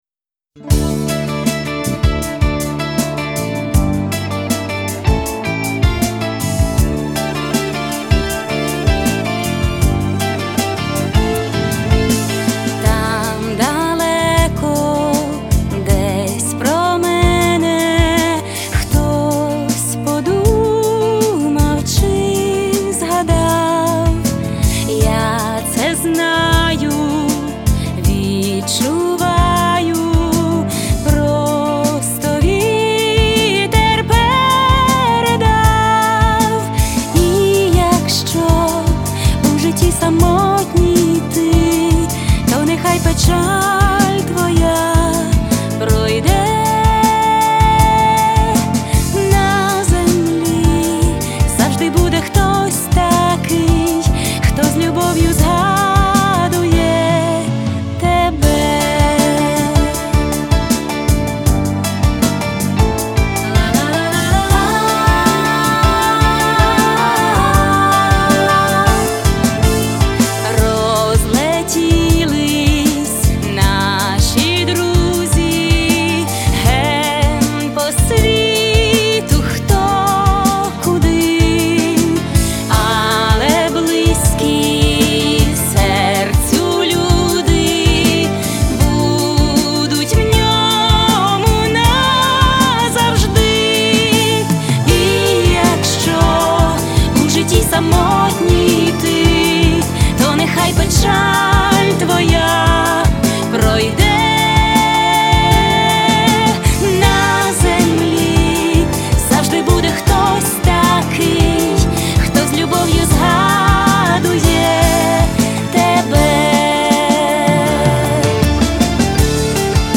Приятный голос!